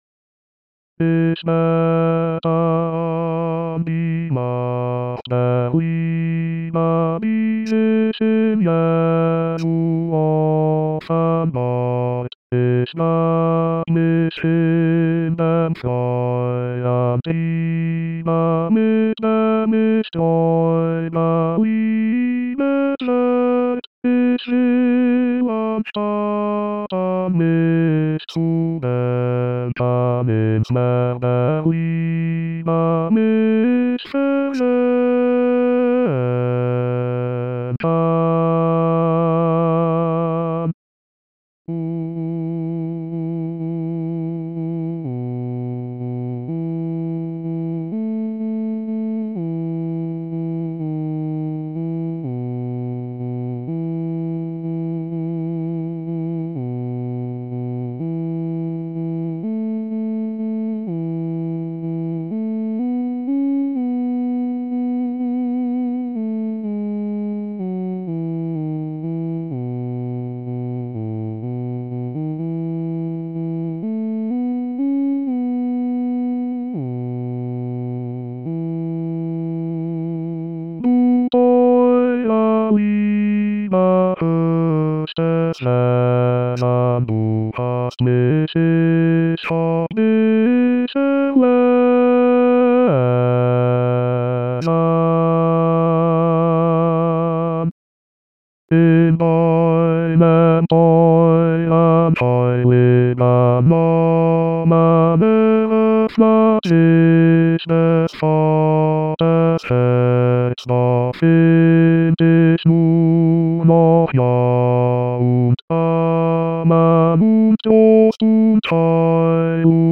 ich bete an die Macht der Liebe basses.mp3